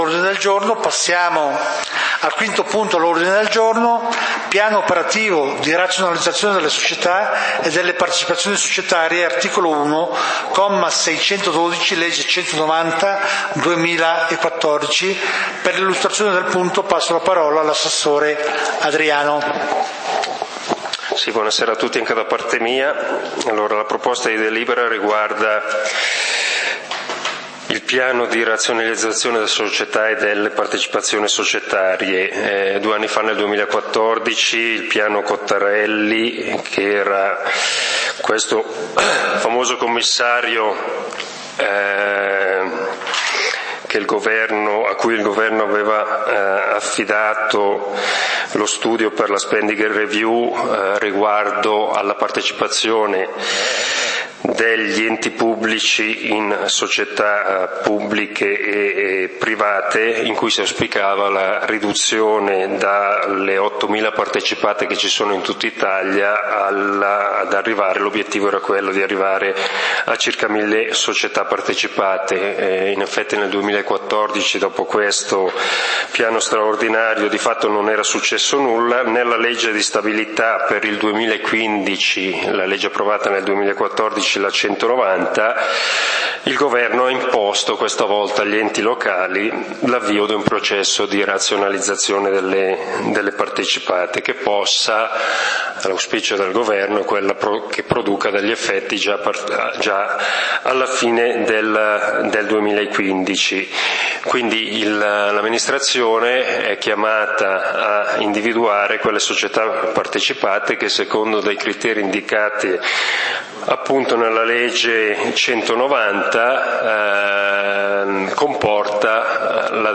Consiglio comunale di Valdidentro del 21 Maggio 2015